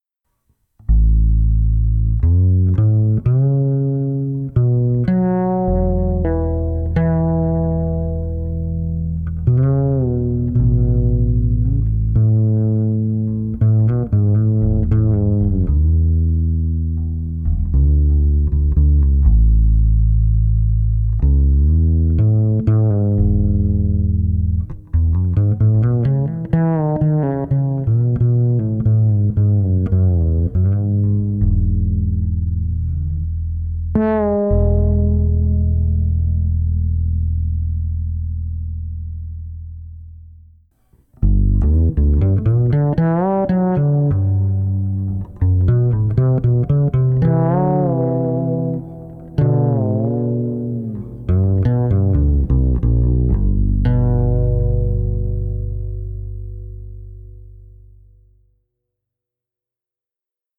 Fretless
fretless.mp3